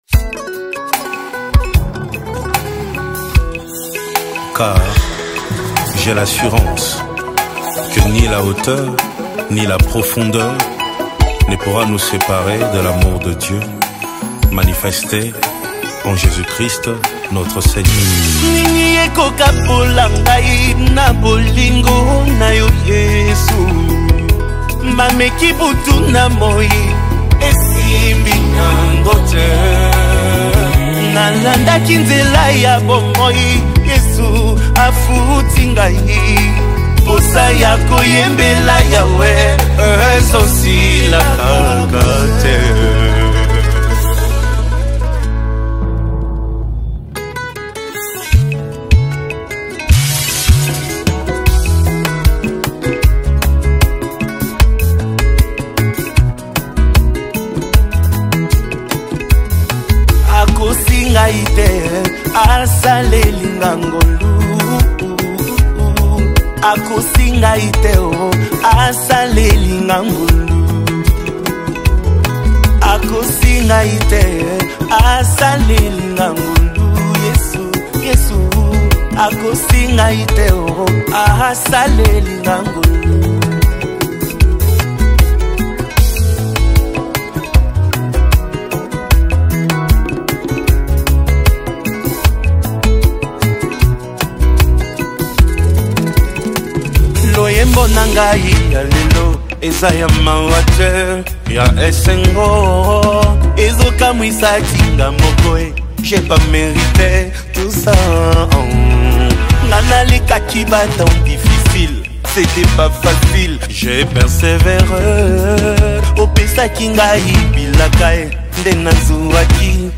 Congo Gospel Music